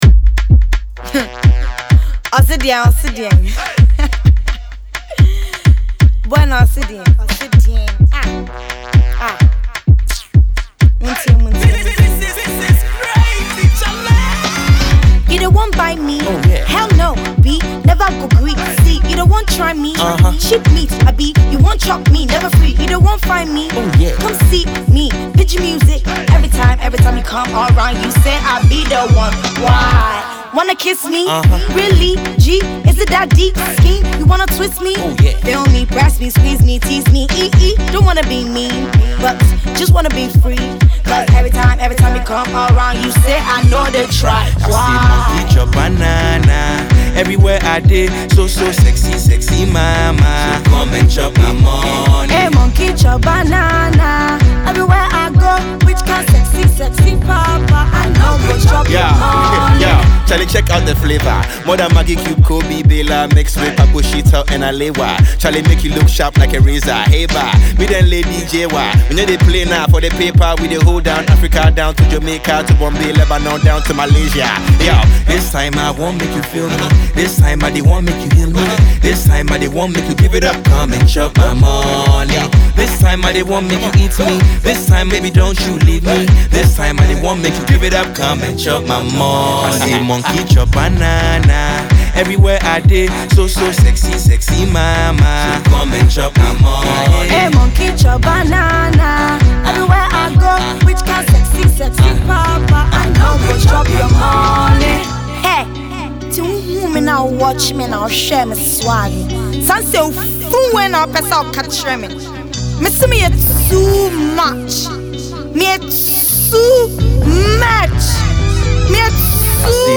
rapping on Azonto-infused production.